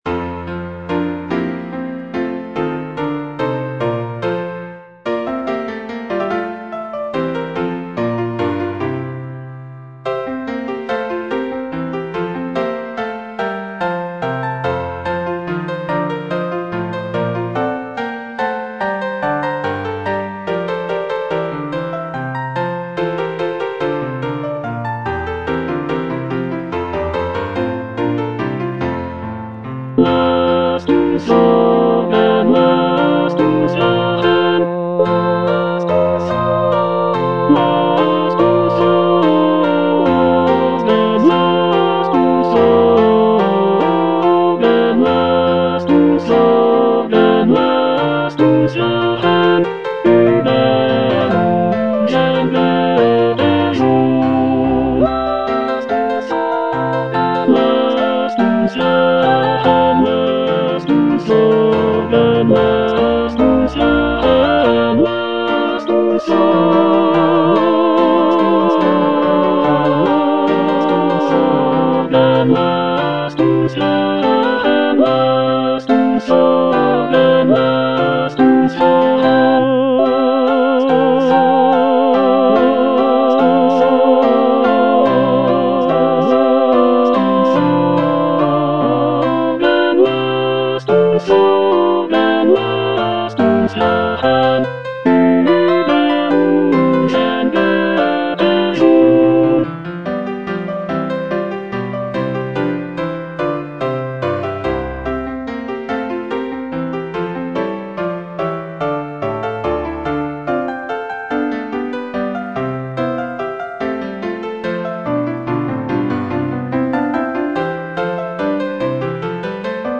Choralplayer playing Cantata
The cantata features a celebratory and joyful tone, with arias and recitatives praising the prince and his virtues. It is scored for soloists, choir, and orchestra, and showcases Bach's mastery of counterpoint and vocal writing.